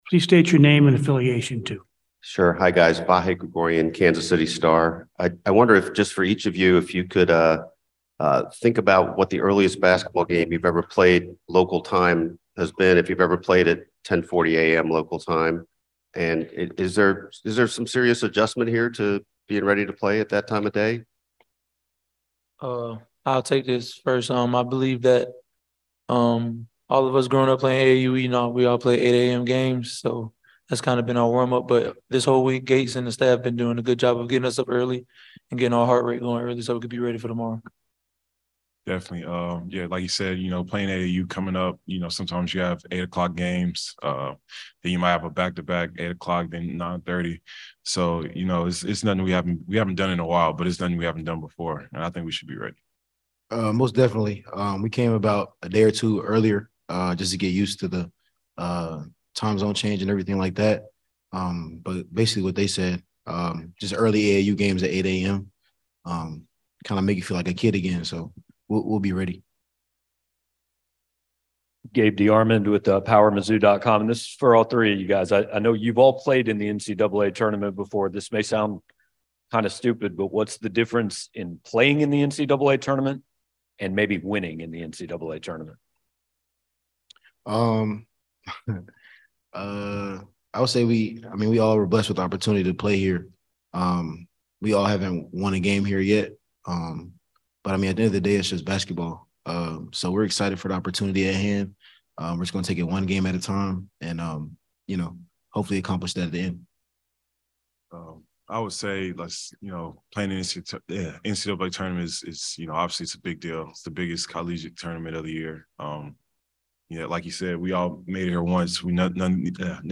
Mizzou full press conference pre-NCAA Tournament